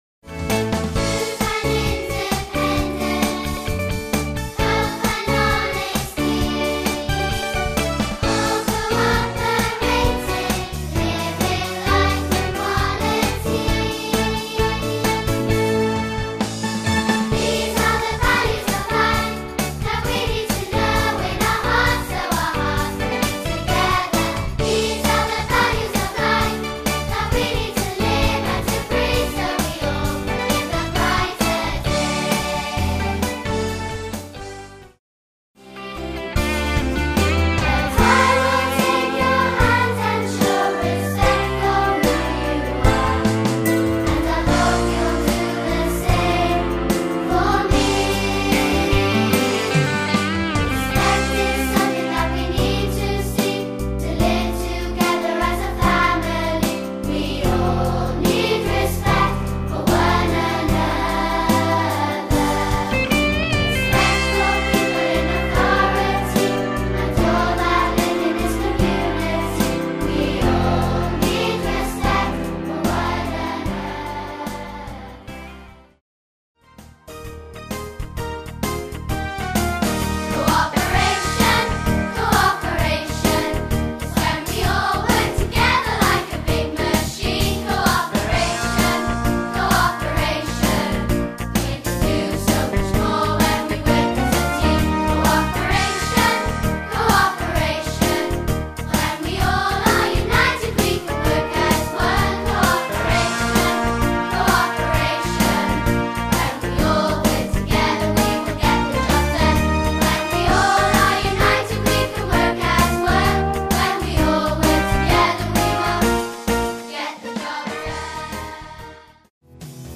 A collection of 15 new assembly songs.